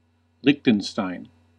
Ääntäminen
Vaihtoehtoiset kirjoitusmuodot Lichtenstein Ääntäminen US UK : IPA : /ˈlɪk.tən.staɪn/ US : IPA : /ˈlɪk.tən.staɪn/ Lyhenteet ja supistumat (laki) Liech.